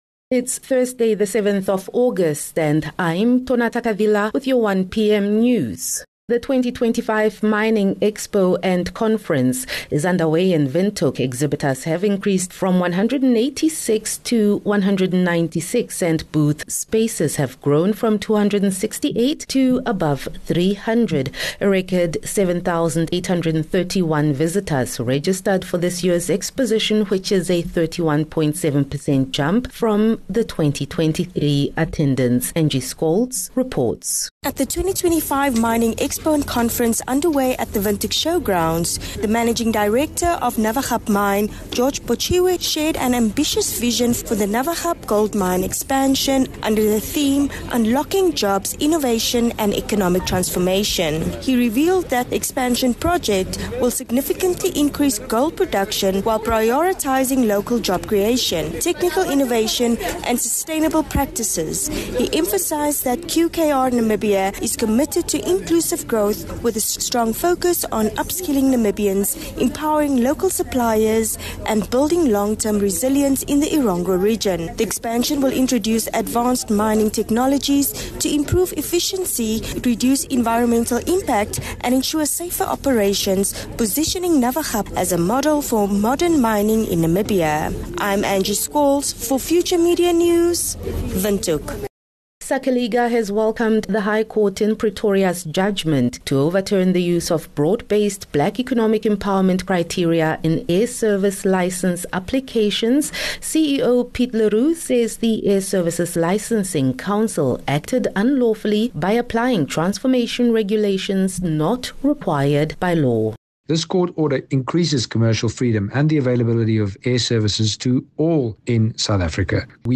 7 Aug 7 August - 1 pm news